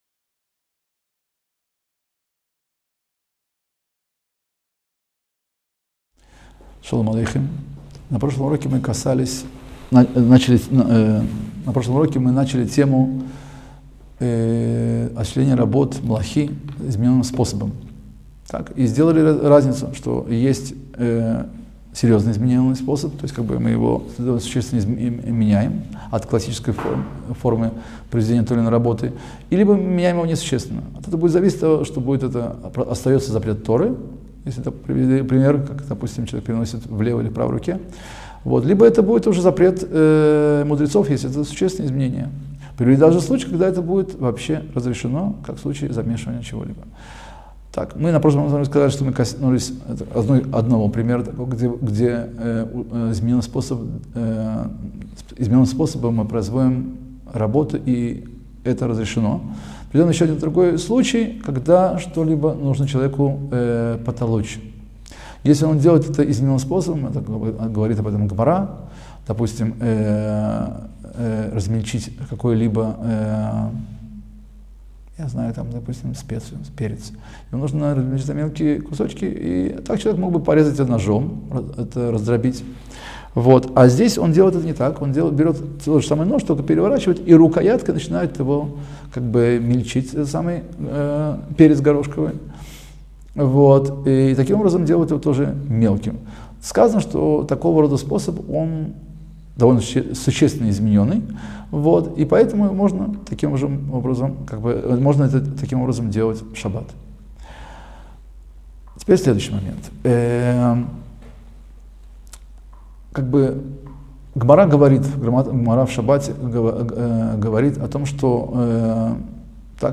Краткие уроки по законам Субботы.